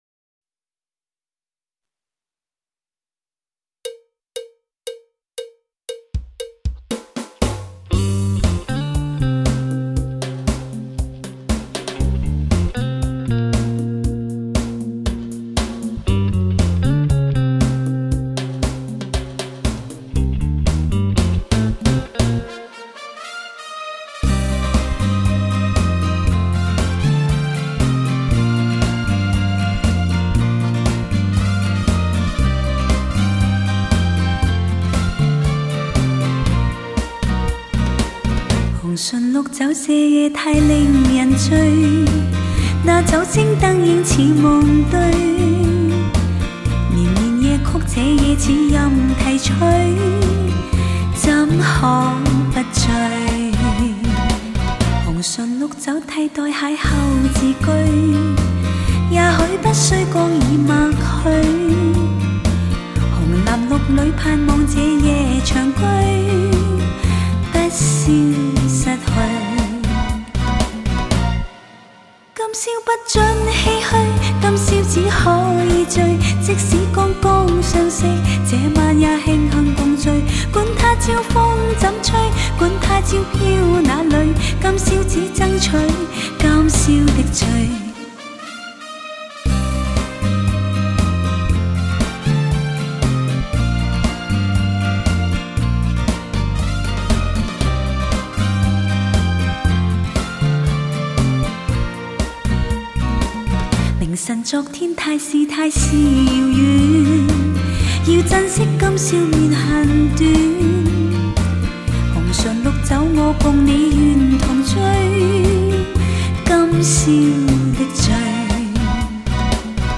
类型: 天籁人声
于唱，声寄于情，无论人声的录音还是演绎都值得一赞！